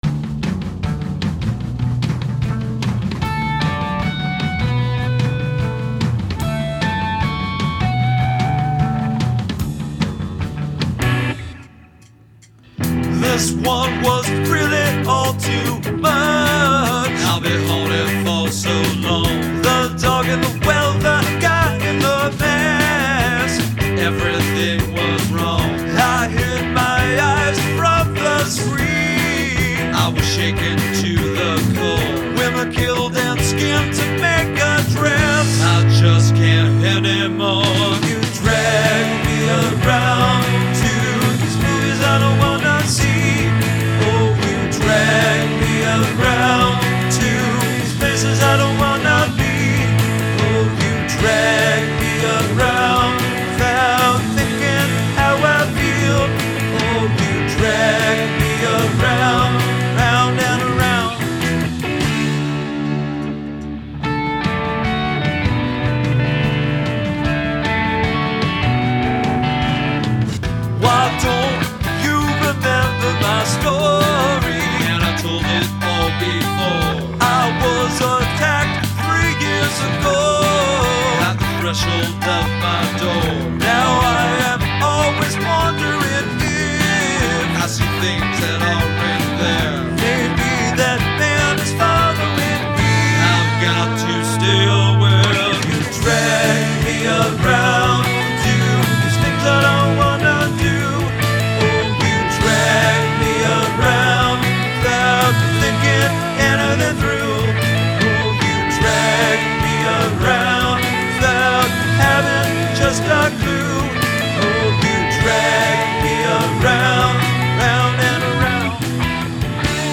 drums, vocals, acoustic guitars
electric guitars
bass